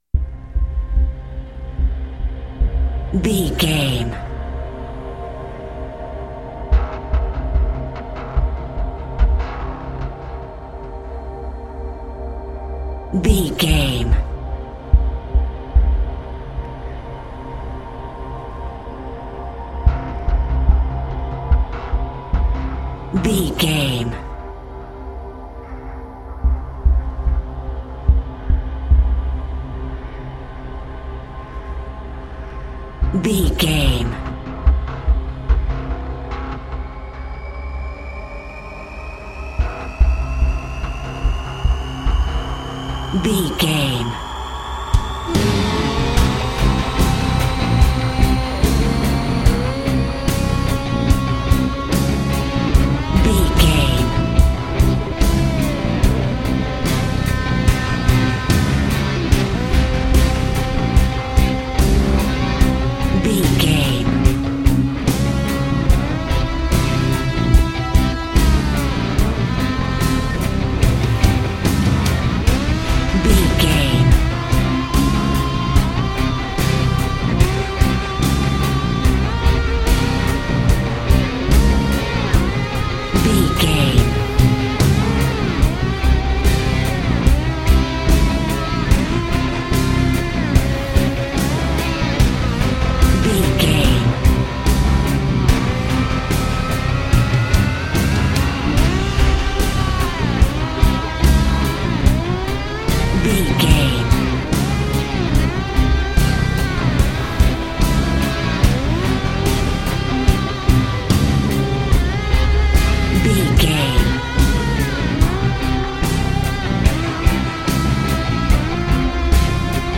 In-crescendo
Thriller
Aeolian/Minor
synthesiser